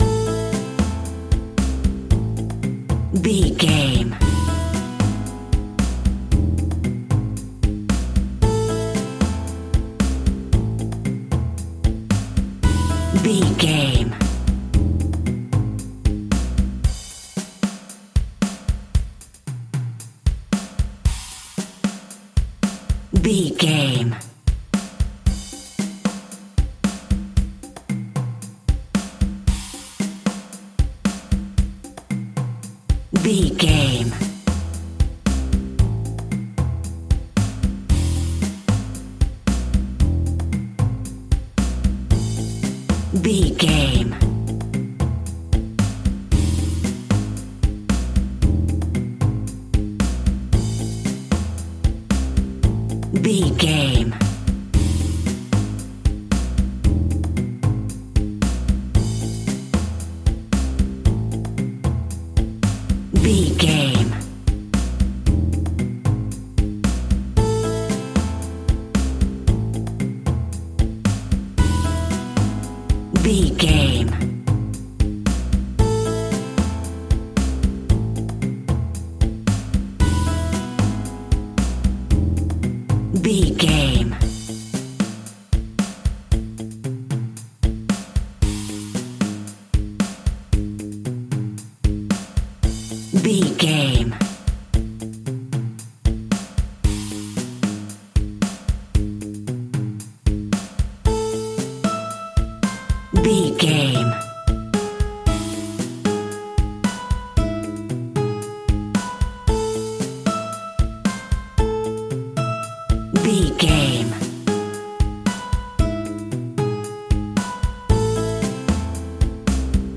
Aeolian/Minor
chilled
laid back
acid jazz
sparse
spacious
lounge music
background instrumentals
spanish guitar
pads